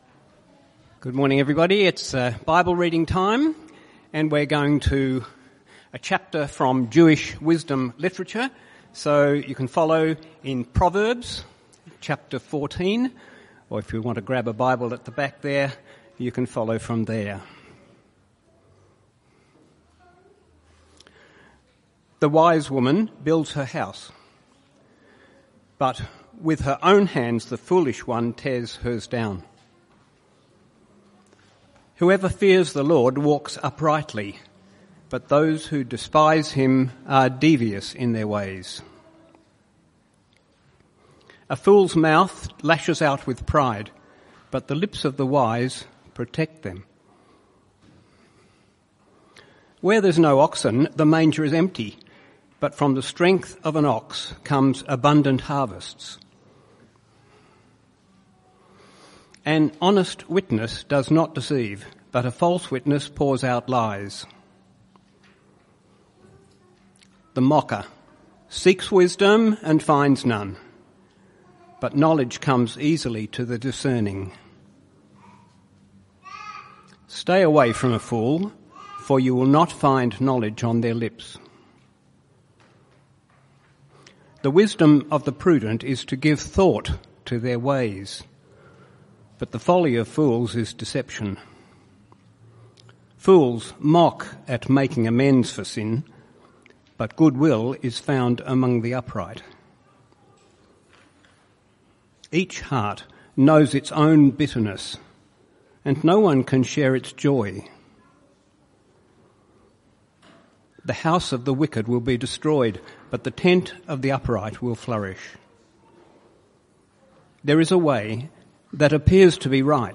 Proverbs 14 Type: Sermons CBC Service